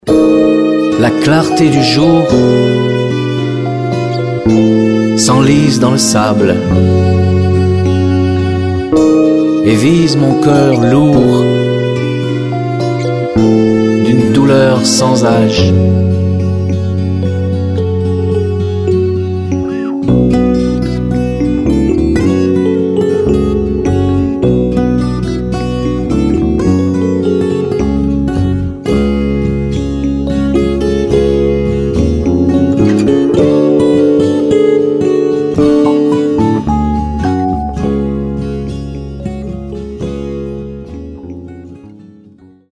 Chansons